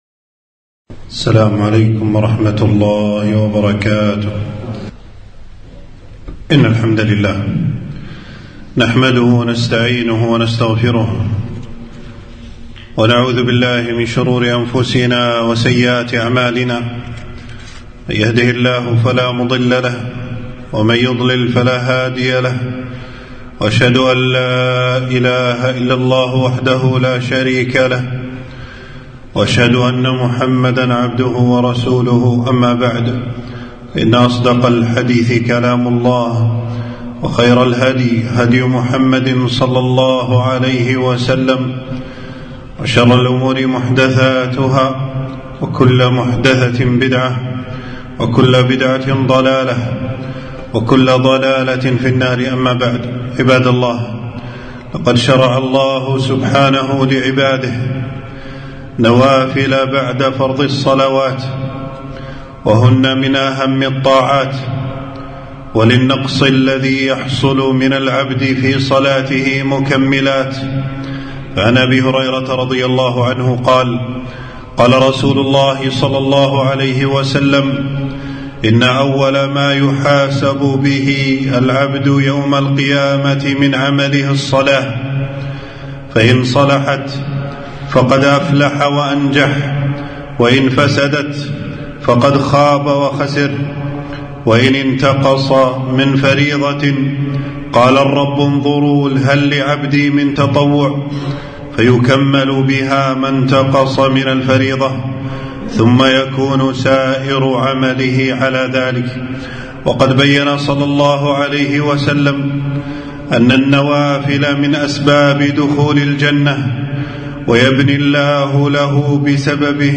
خطبة - المحافظة على نوافل الصلوات من أهم الطاعات